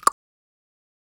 claquement-3.wav